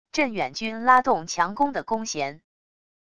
镇远军拉动强弓的弓弦wav音频